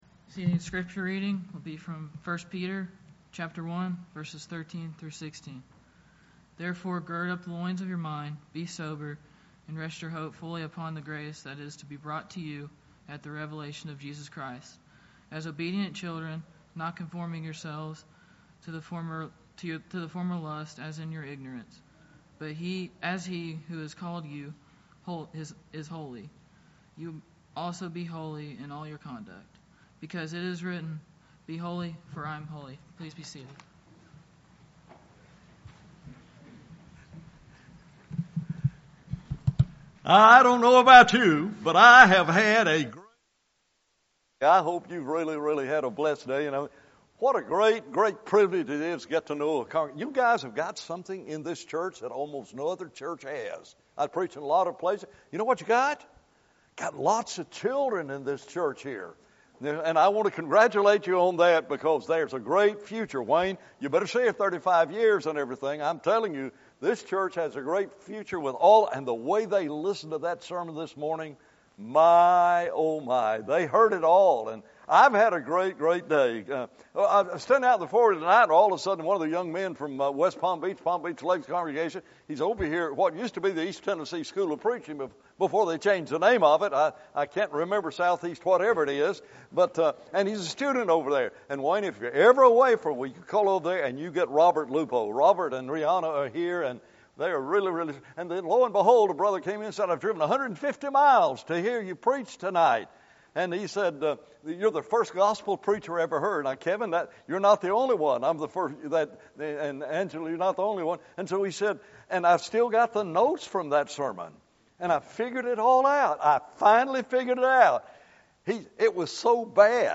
1 Peter 1:13-16 Service Type: Gospel Meeting « Brute Beasts or Living Souls?